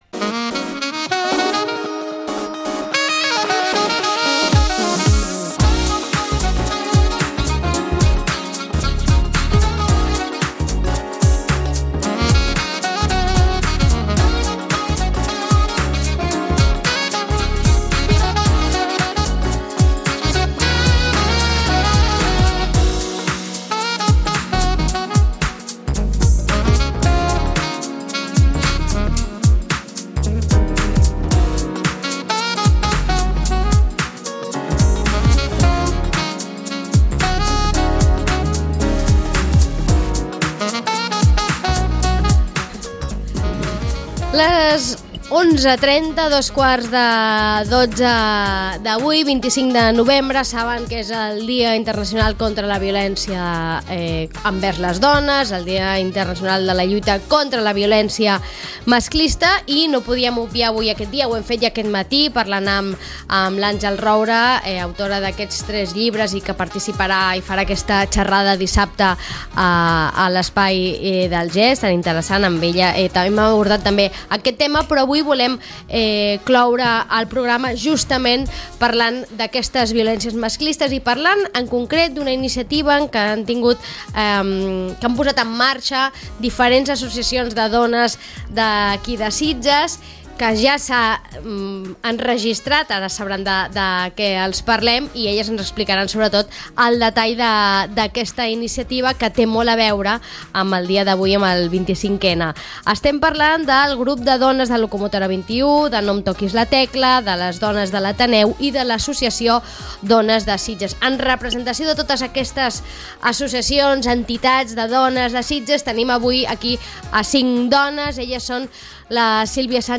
Amb representants de les diferents associacions de dones conversem sobre aquesta iniciativa del podcast i sobre la necessitat de parlar obertament sobre aquesta xacra que és la violència masclista.